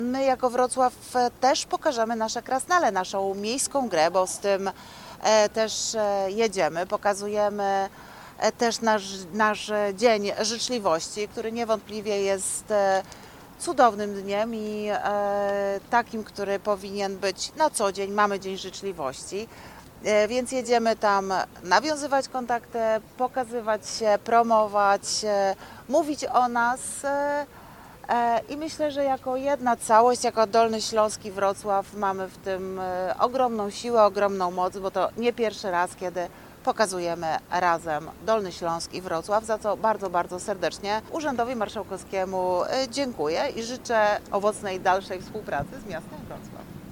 Mówi wiceprezydent Wrocławia Renata Granowska.